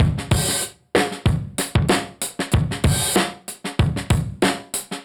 Index of /musicradar/dusty-funk-samples/Beats/95bpm/Alt Sound